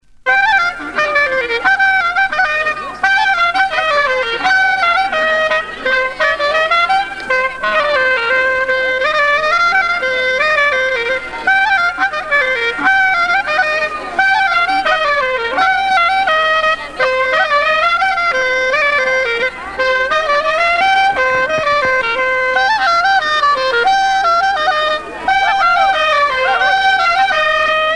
danse : ronde
Sonneurs de clarinette